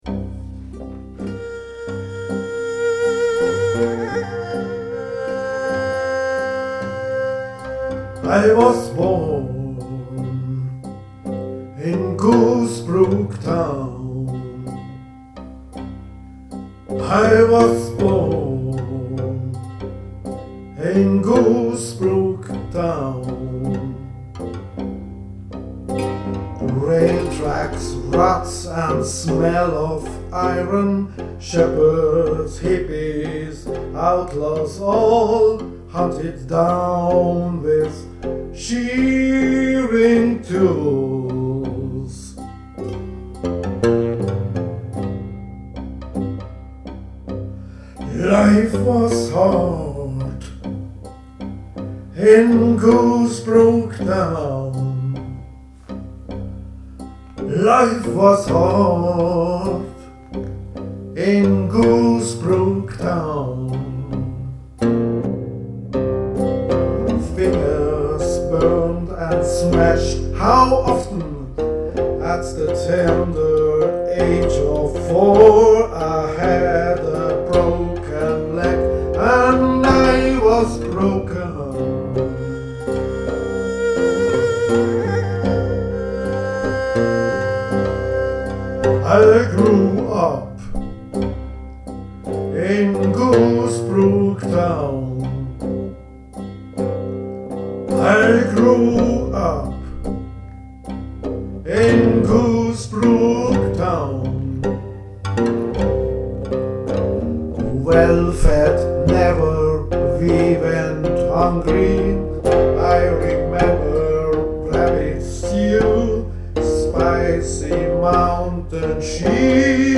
Blues-zweite-Version-bounced-.mp3